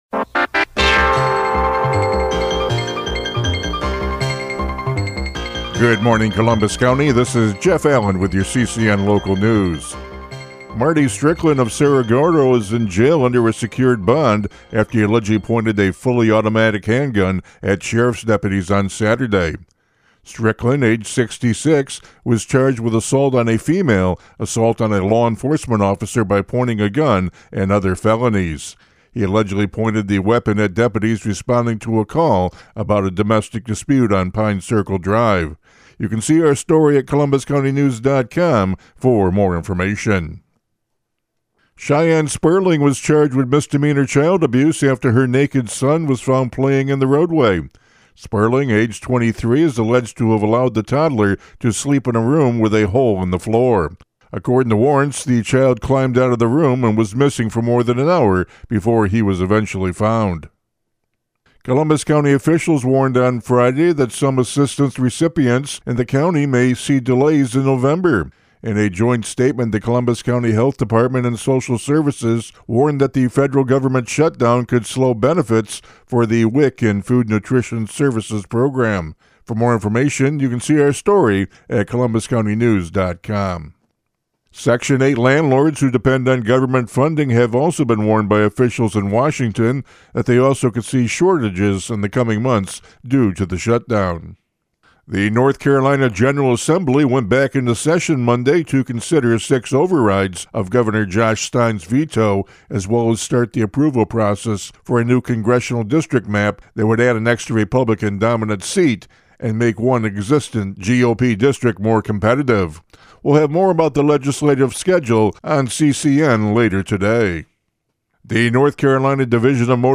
CCN Radio News — Morning Report for October 21, 2025